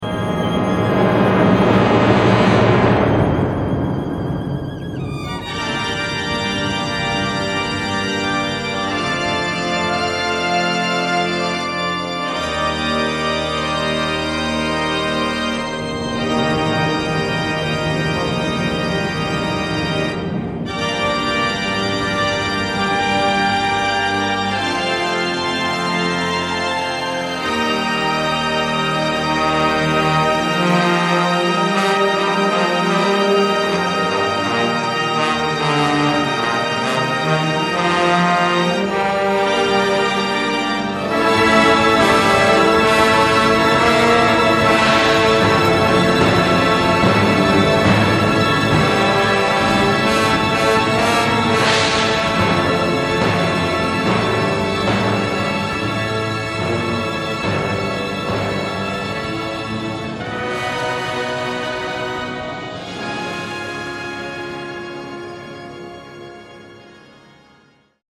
mellotron
classical and operatic themes
Digitally remastered from the original tapes